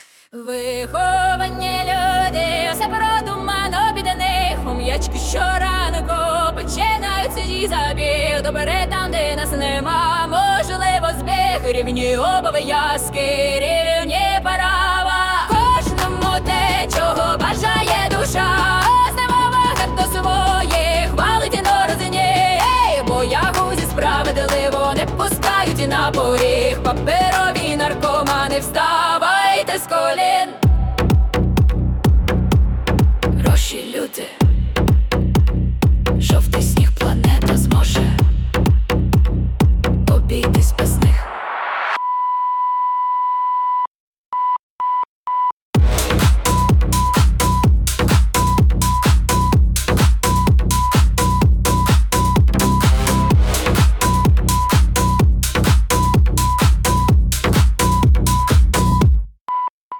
Гроші, люди, жовтий сніг (+ 🎧 музична версія)
СТИЛЬОВІ ЖАНРИ: Ліричний